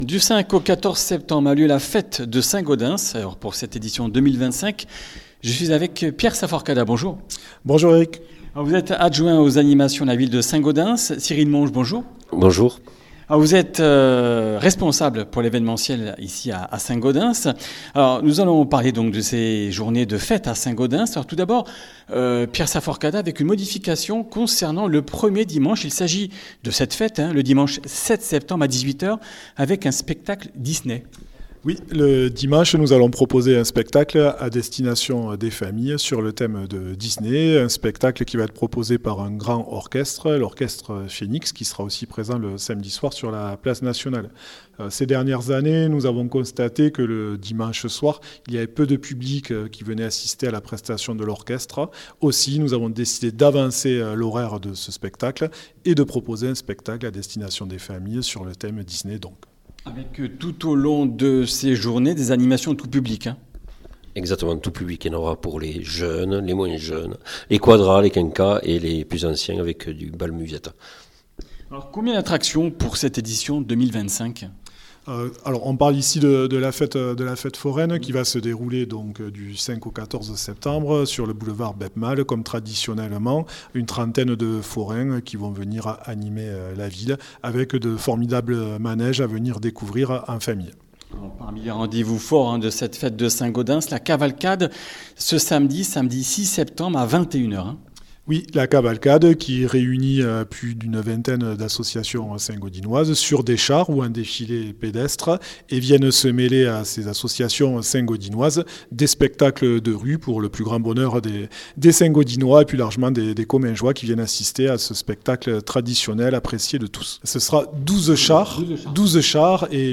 mardi 2 septembre 2025 Comminges Interviews Durée 25 min